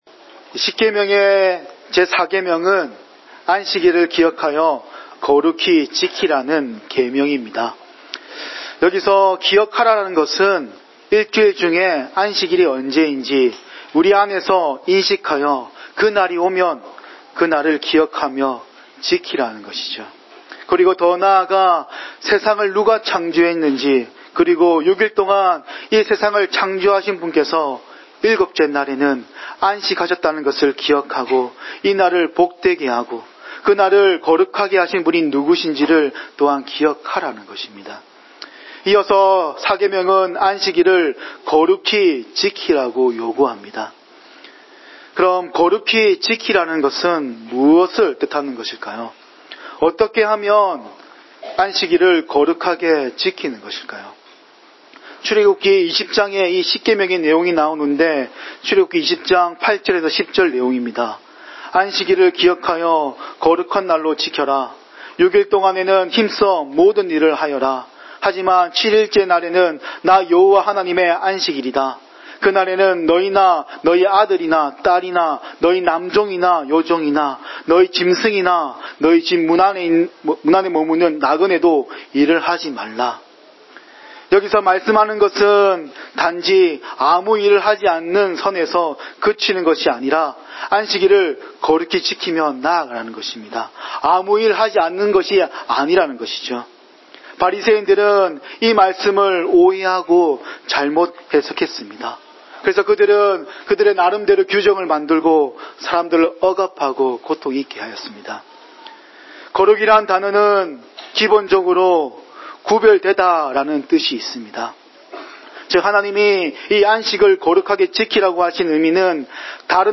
주일 목사님 설교를 올립니다.